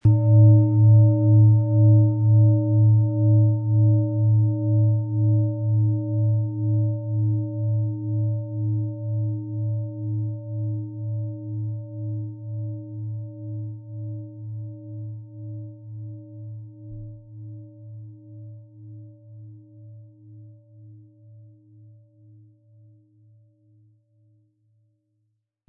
Venus
• Mittlerer Ton: Wasserstoffgamma
HerstellungIn Handarbeit getrieben
MaterialBronze